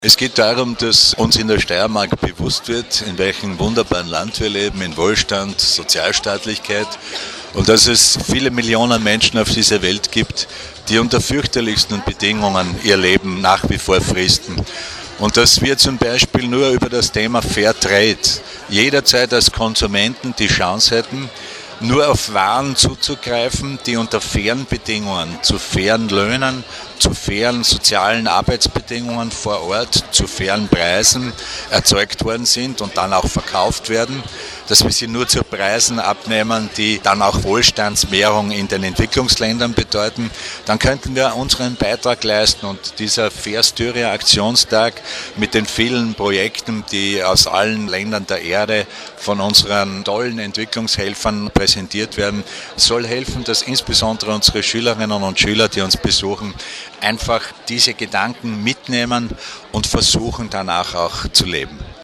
O-Ton: FairStyria-Aktionstag in Graz
Landeshauptmann Franz Voves